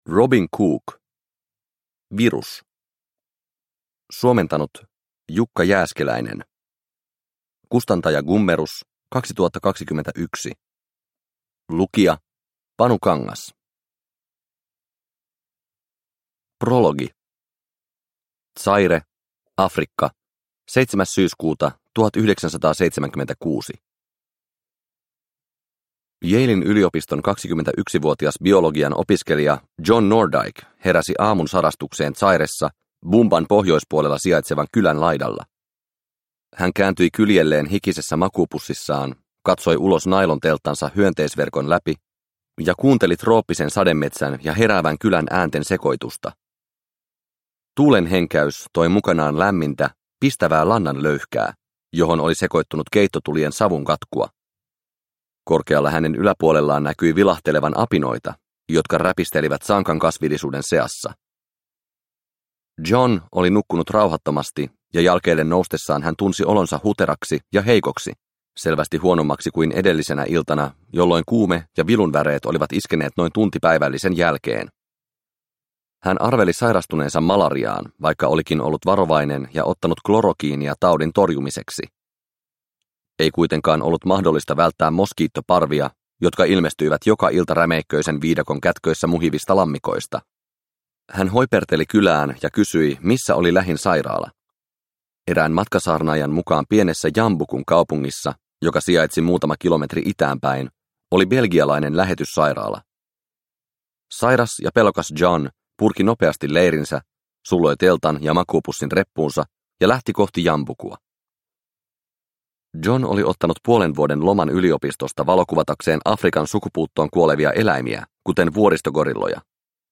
Virus – Ljudbok